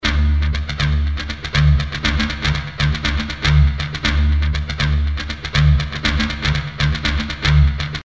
单线圈静音
描述：电吉他静音节奏与Alesis Fusion合成器一起完成
Tag: 120 bpm Dance Loops Guitar Electric Loops 1.34 MB wav Key : Unknown